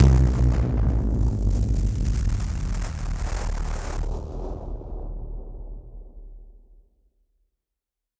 BF_SynthBomb_A-02.wav